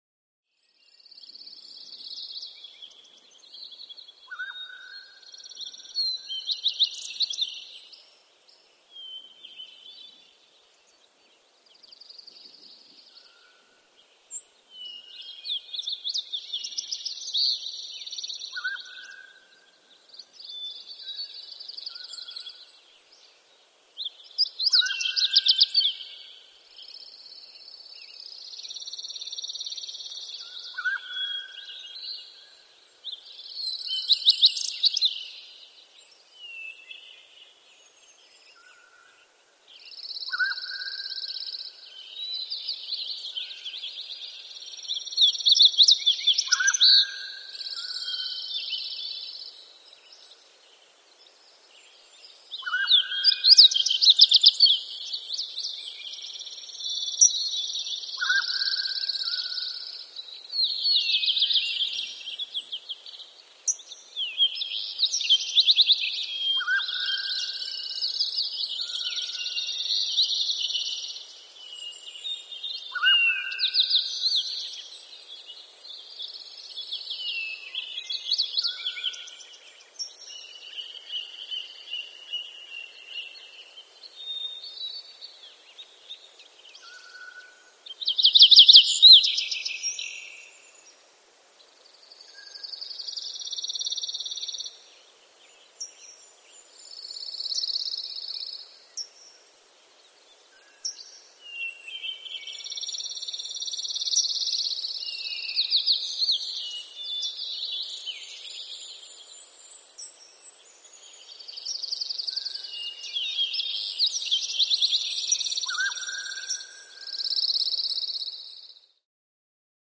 Mountains
Mountain Ambience With Close Perspective Bird Song And Echoey Exotic Bird Call In The Background ( Version 1 ).